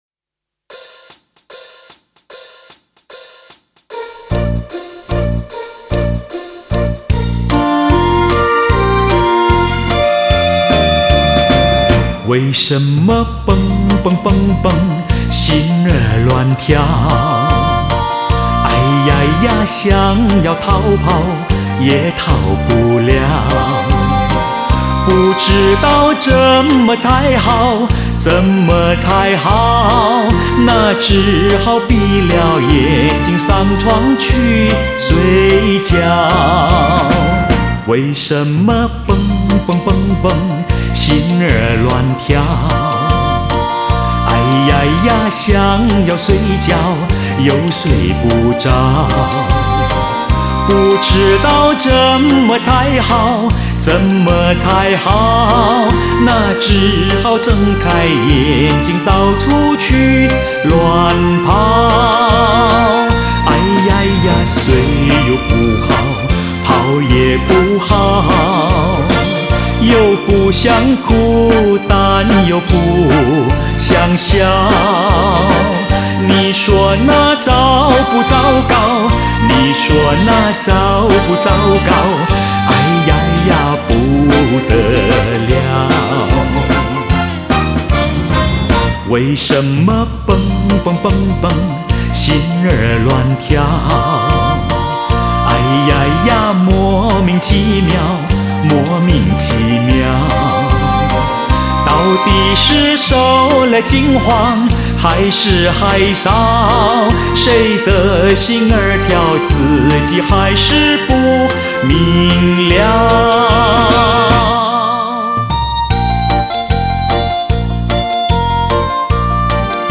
大姐的专辑很抒情！